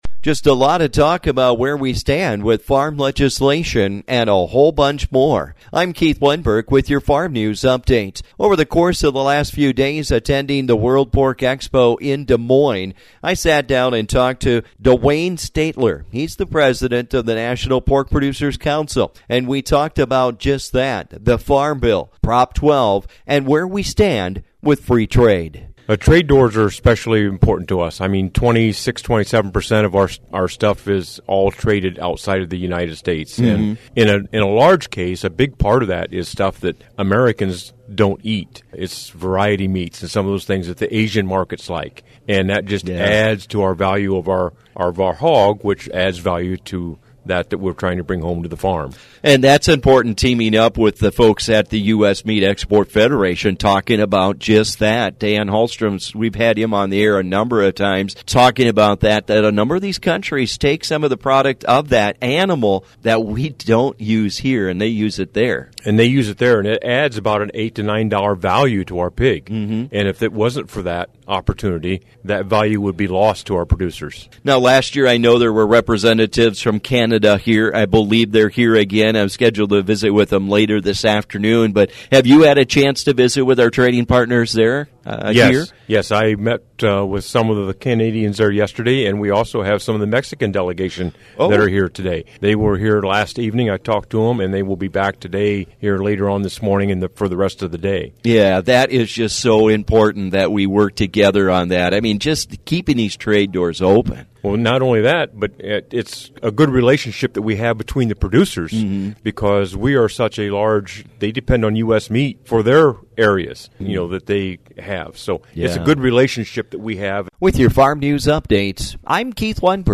While attending World Pork Expo, there was a lot of talk about farm legislation and where we stand with it.